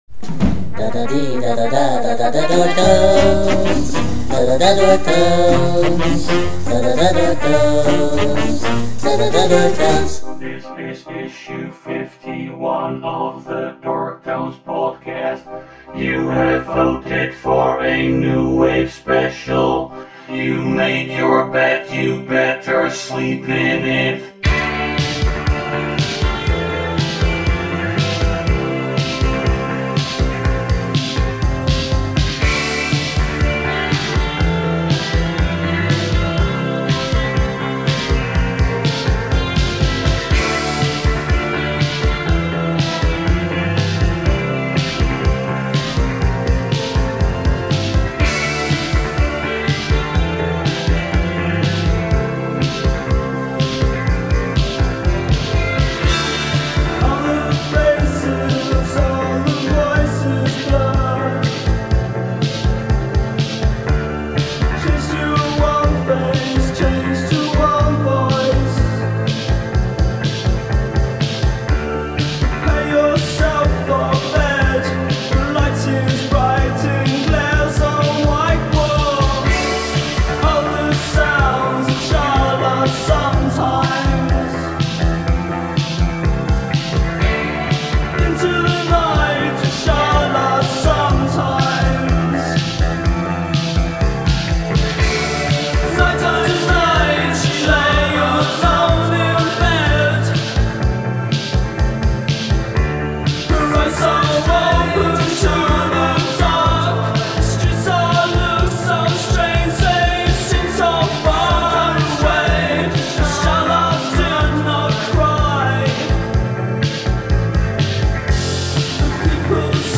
New Wave special